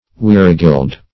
weregeld - definition of weregeld - synonyms, pronunciation, spelling from Free Dictionary